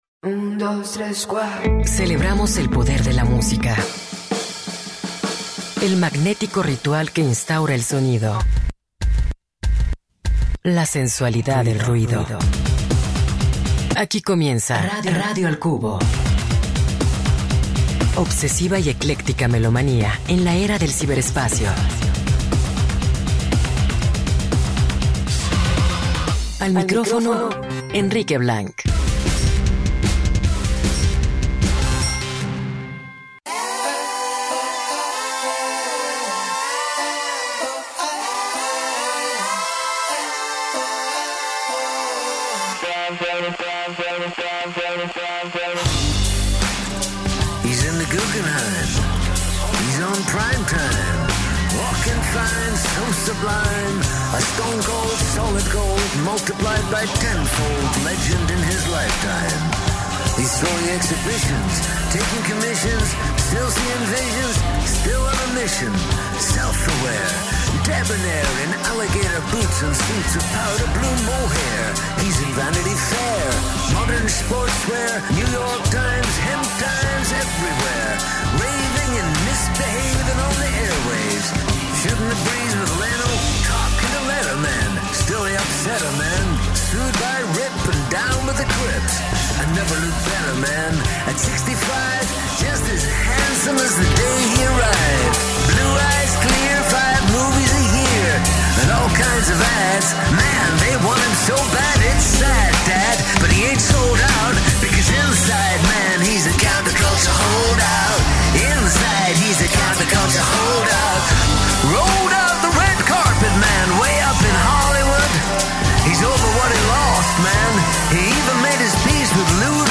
El día de hoy tenemos una entrevista muy buena